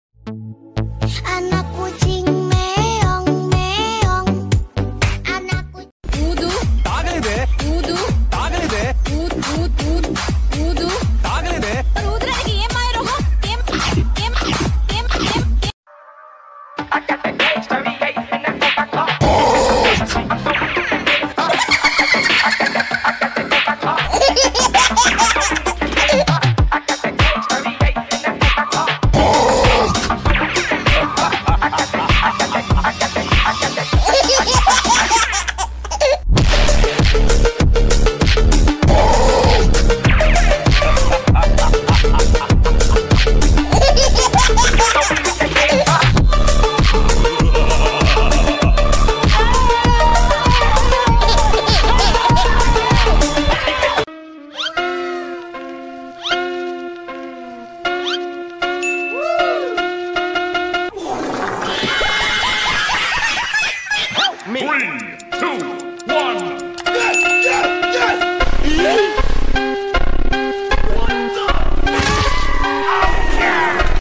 This funny cartoon is full of silly faces, crazy voices, and totally unexpected moments.